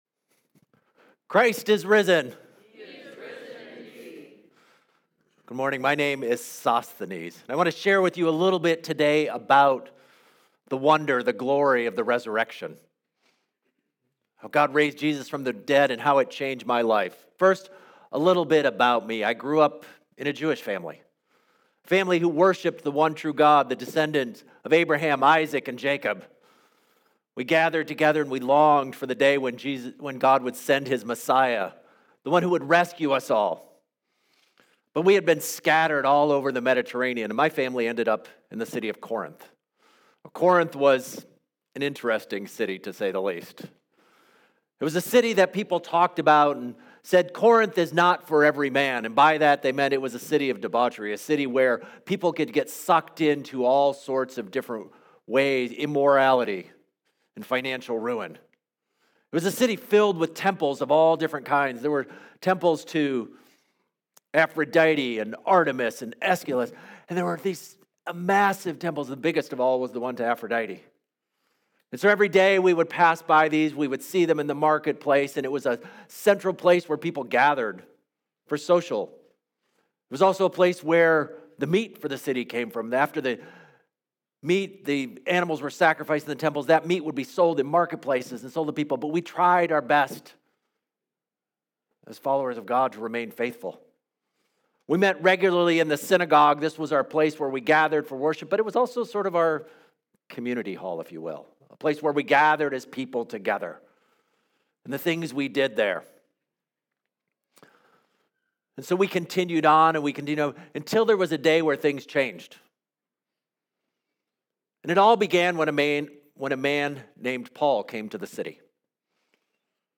This sermon is told from the perspective of Sosthenes.